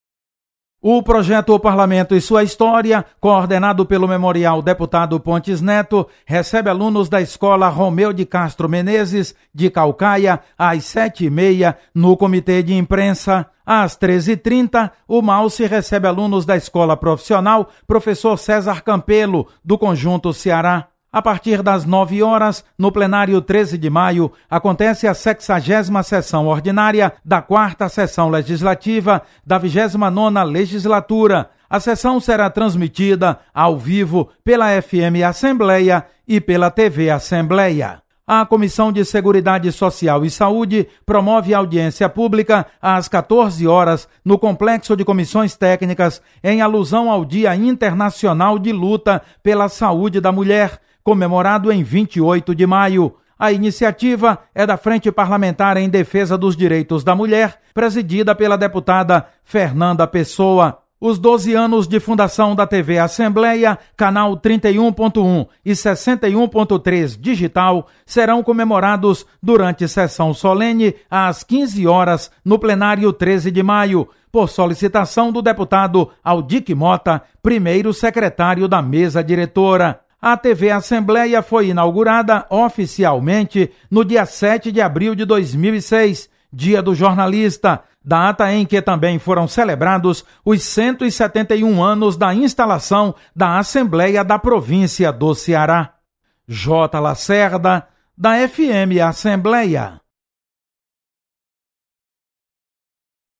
Acompanhe as atividades de hoje com o repórter